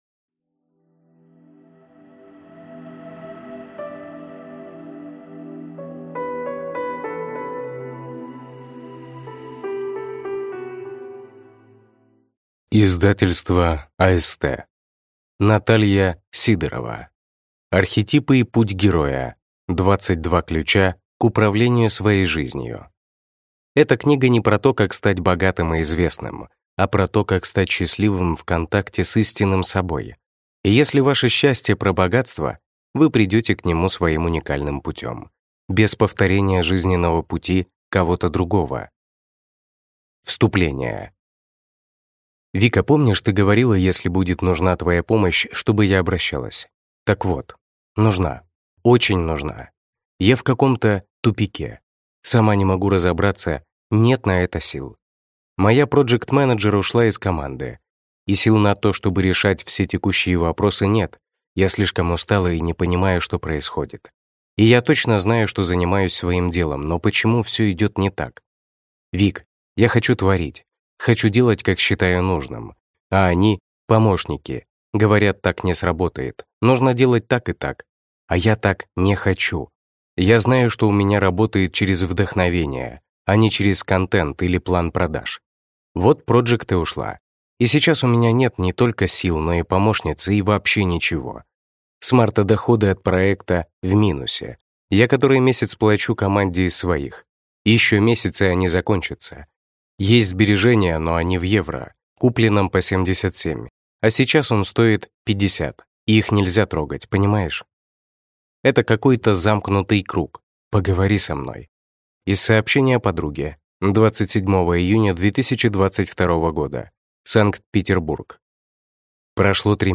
Аудиокнига Архетипы и Путь Героя. 22 ключа к управлению своей жизнью | Библиотека аудиокниг